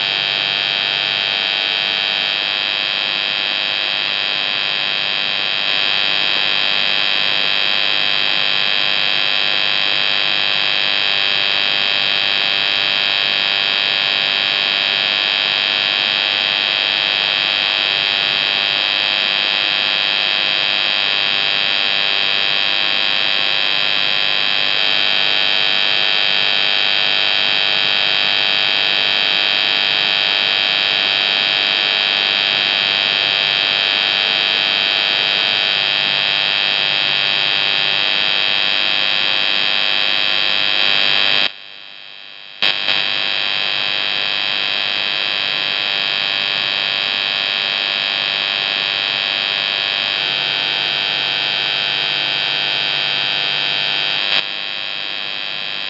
Переходные процессы в линии связи.
2258khz_af.wav